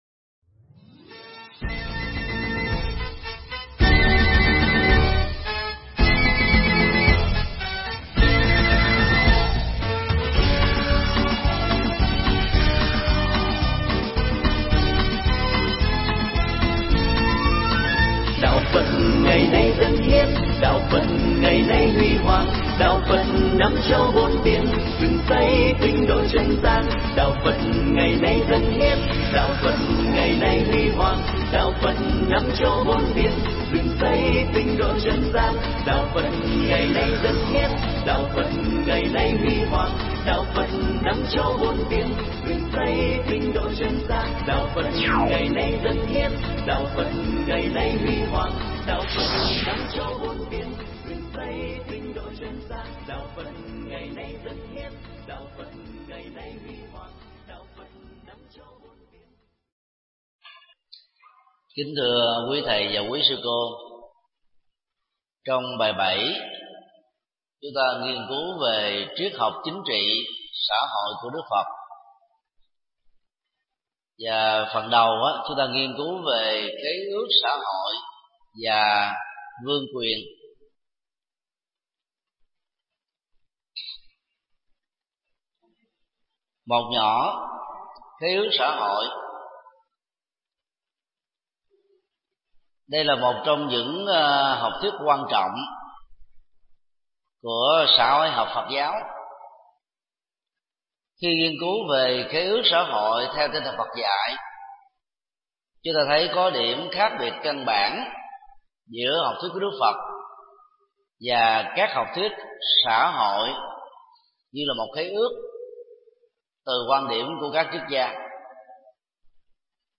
Mp3 Pháp thoại Dẫn Nhập Triết Học Phật Giáo 7
tại chùa Phổ Quang